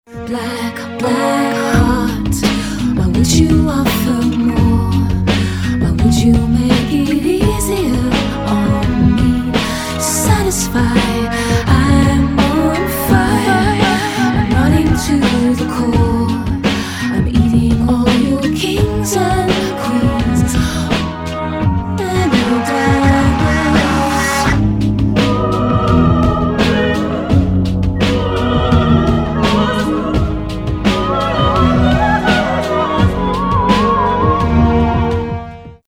баллады , pop rock